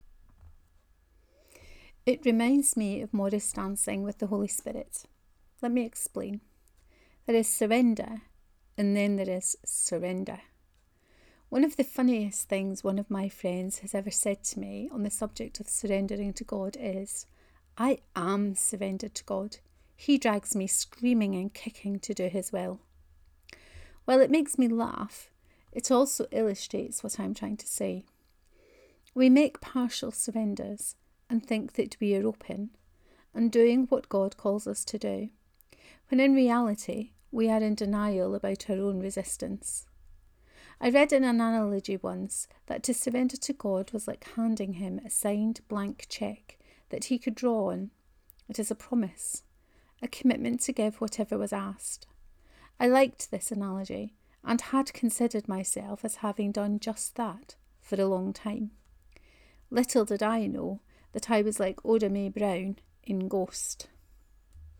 Morris dancing with the Holy Spirit 2: Reading of this post.